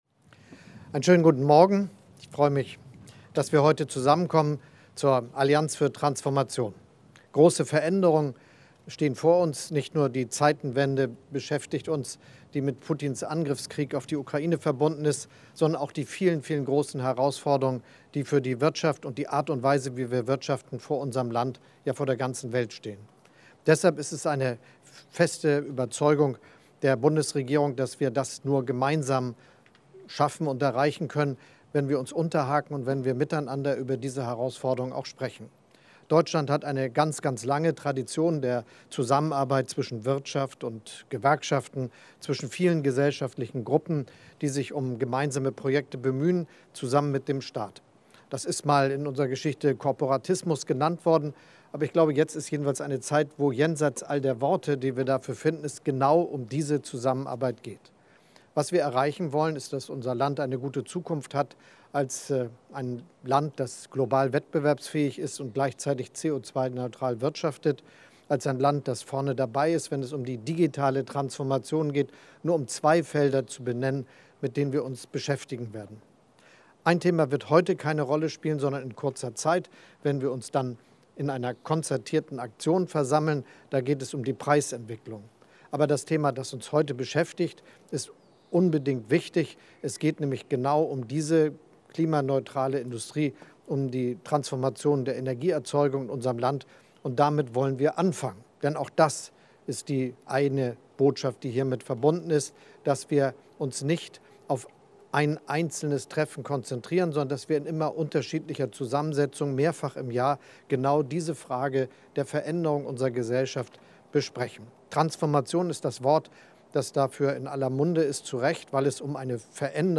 Pressestatements zum Auftakt der Allianz für Transformation ⏐ Bundesregierung